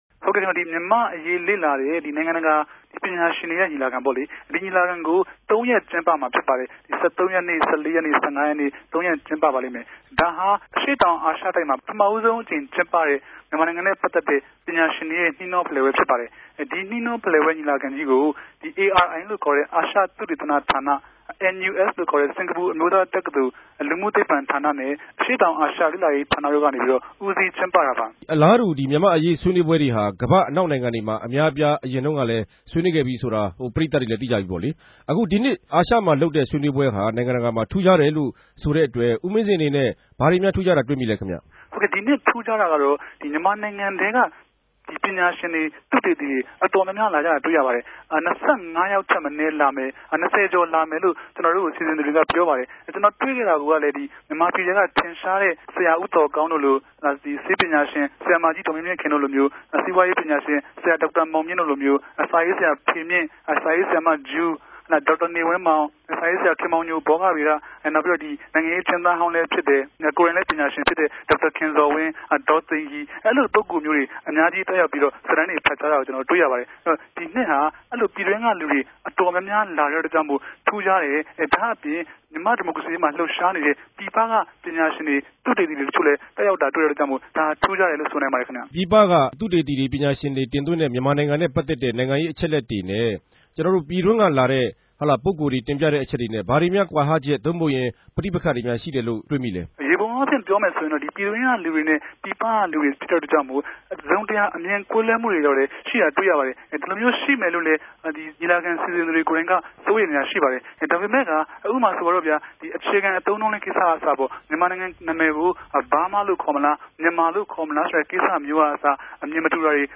ဆက်သြယ်မေးူမန်းထားတာ နားထောငိံိုင်ပၝတယ်။